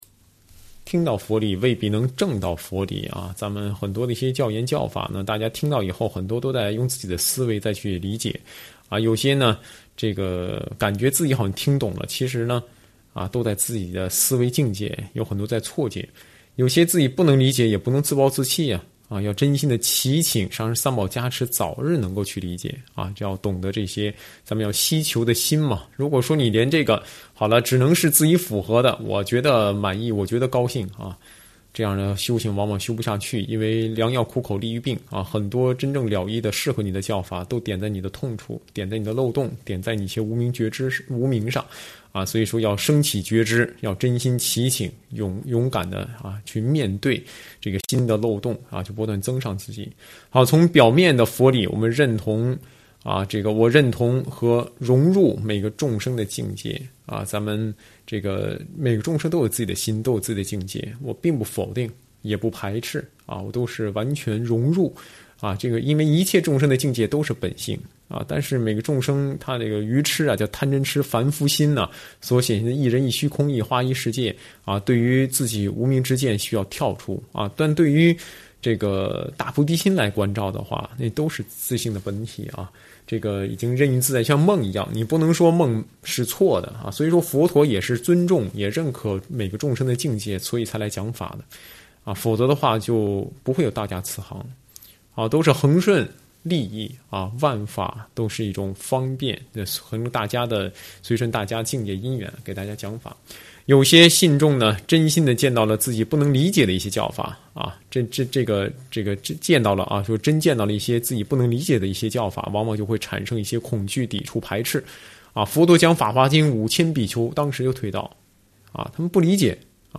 随笔开示